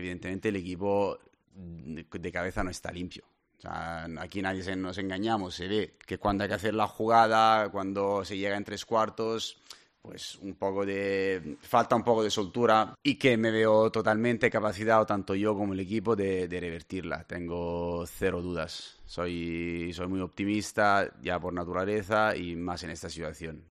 “Tengo cero dudas, tanto yo como el equipo veo que tiene la capacidad de revertirlo, soy optimista por naturaleza y más en esta ocasión. Cualquiera sería optimista viendo a la plantilla”, indicó en la rueda de prensa previa al partido del sábado ante el Espanyol a domicilio.